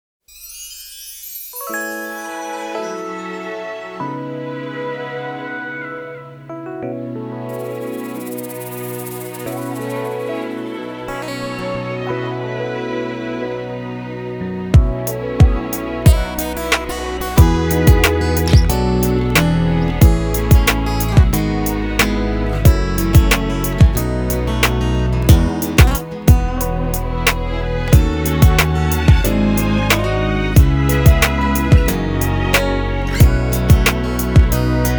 K-Pop Pop
Жанр: Поп музыка